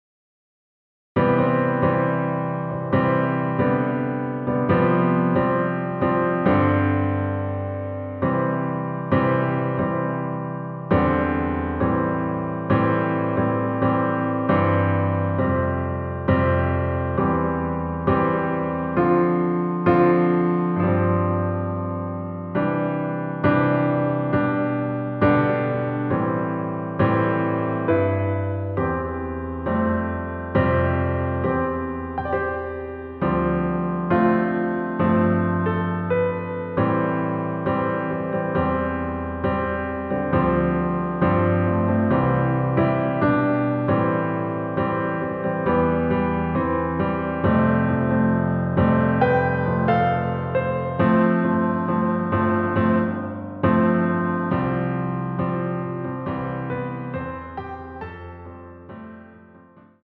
반주를 피아노 하나로 편곡하여 제작하였습니다.
원키에서(-1)내린 (Piano Ver.) MR입니다.